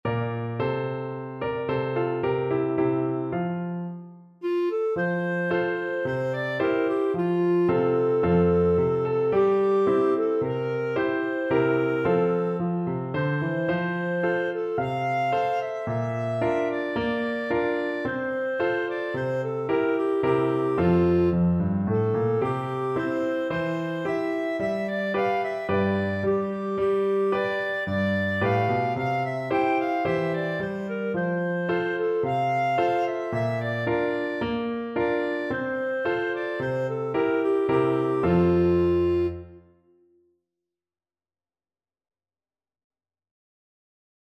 Moderately fast =c.110
4/4 (View more 4/4 Music)
Classical (View more Classical Clarinet Music)